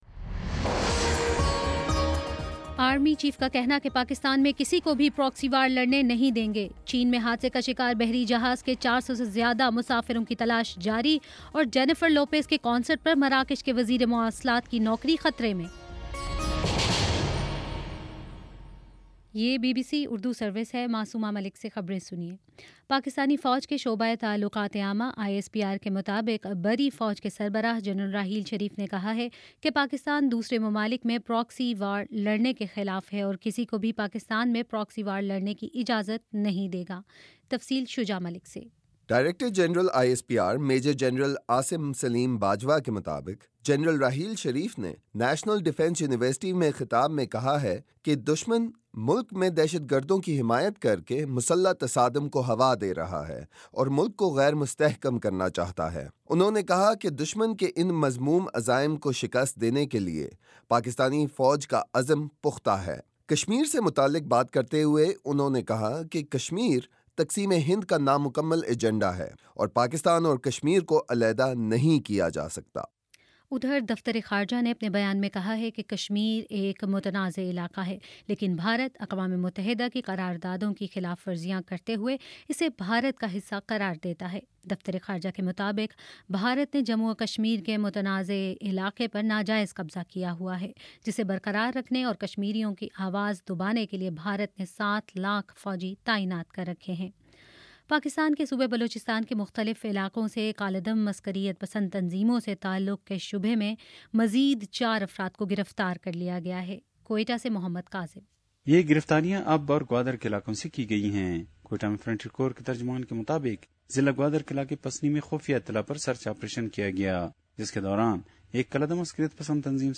جون 3: شام چھ بجے کا نیوز بُلیٹن